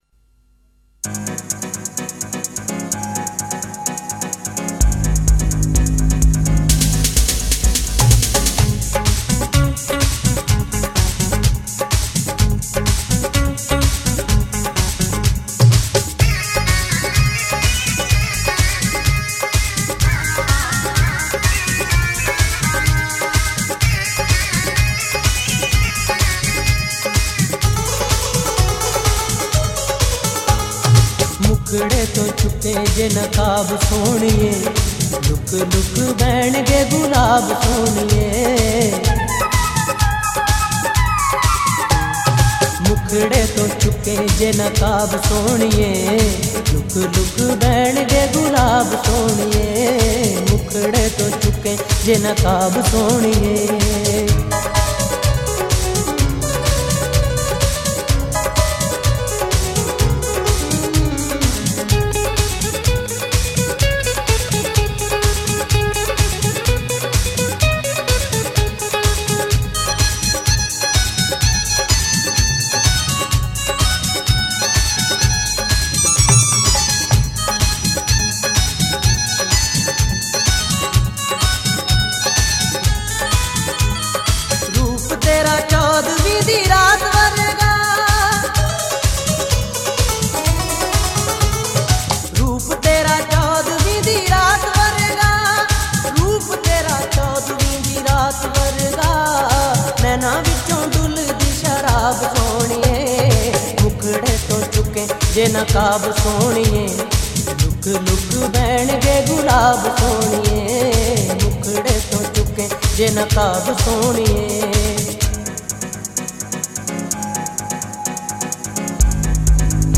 Punjabi Qawwali and Sufiana Kalam